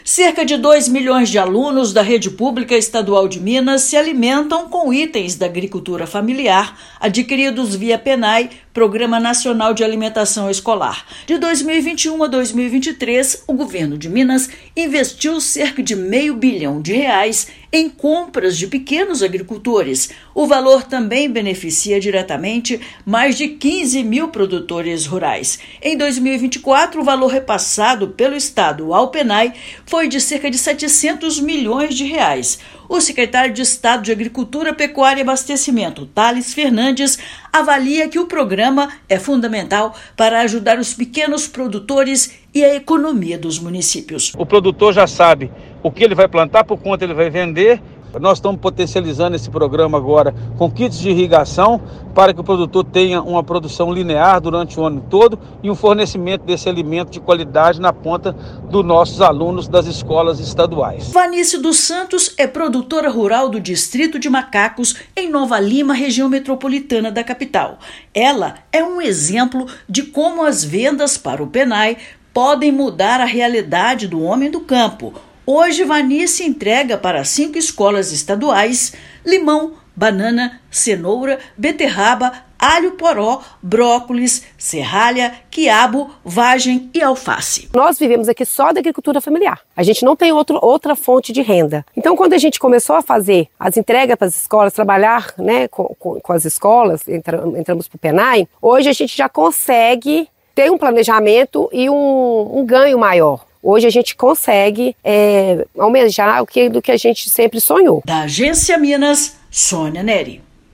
Agricultores de 817 municípios auxiliados pela Emater-MG aderiram ao Programa Nacional de Alimentação Escolar (Pnae) e vendem alimentos frescos para escolas públicas. Ouça matéria de rádio.